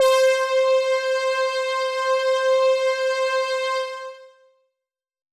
Synth Pad C5.wav